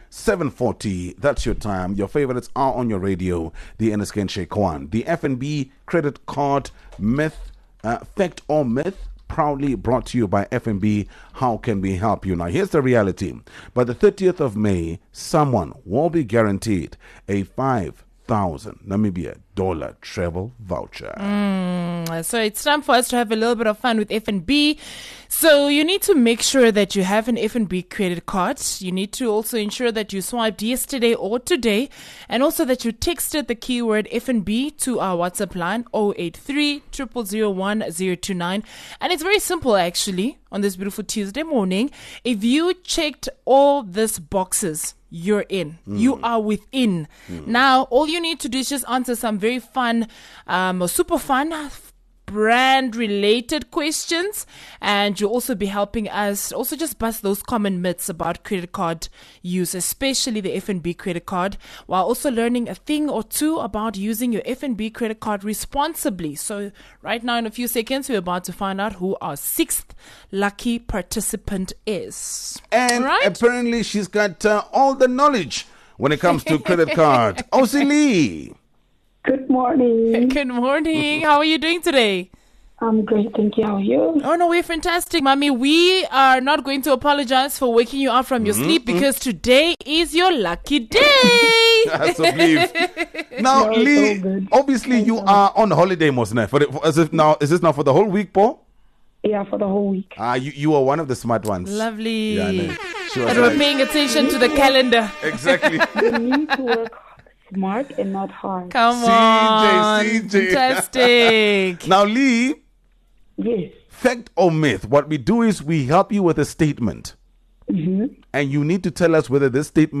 Fresh FM and FNB are here to put you to the test — with a fun, fast-paced segment that’s all about busting myths and rewarding facts! A game in which you could walk away with a N$5,000 travel voucher!